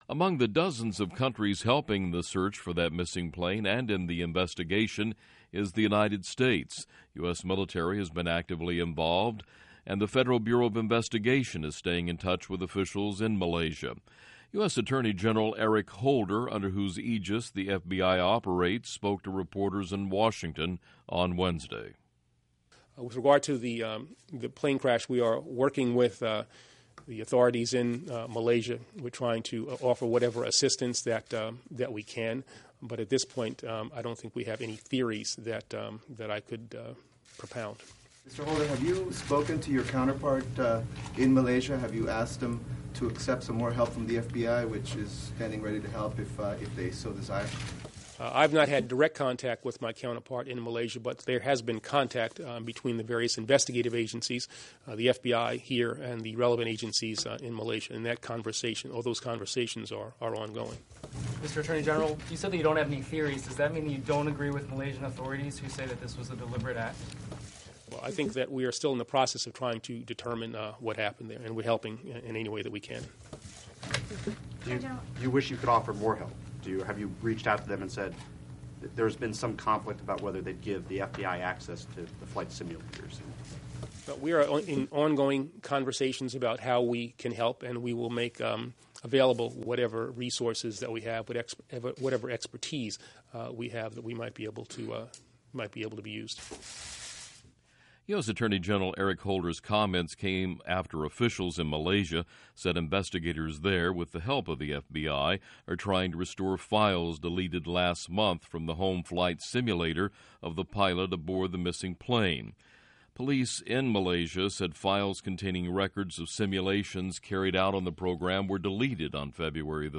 Q&A Reporters / Eric Holder / Malaysia Plane / US / FBI
The US Military Has Been Actively Involved ... And The Federal Bureau Of Investigation Is Staying In Touch With Officials In Malaysia. US Attorney General Eric Holder, Under Who Aegis The F-B-I Operates, Spoke To Reporters In Washington Wednesday.